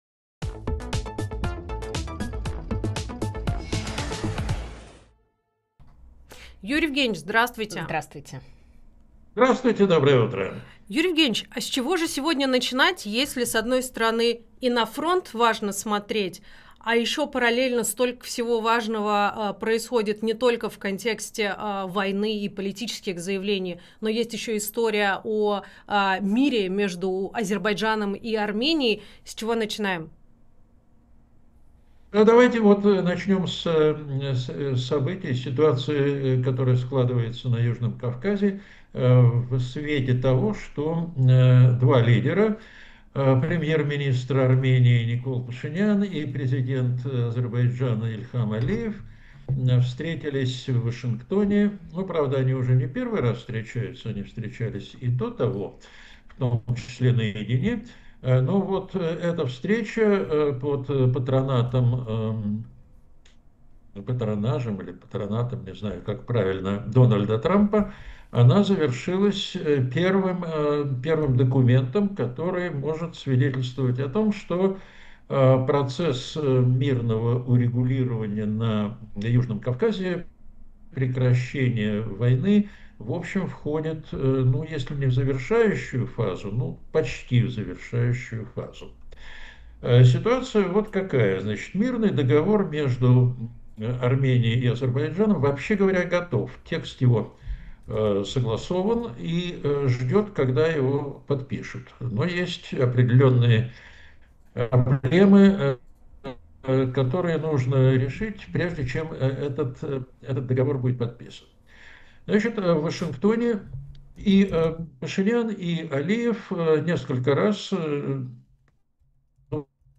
Фрагмент эфира от 9 августа.